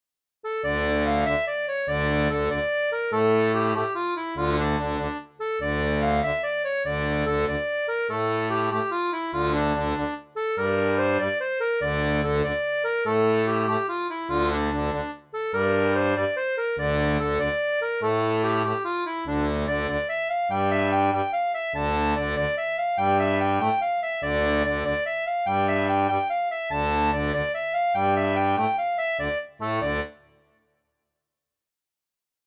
Air traditionnel portugais
• Une tablature pour diato à 3 rangs à la tonalité originale
Extrait audio généré numériquement à partir de la tablature pour 3 rangs :
Folk et Traditionnel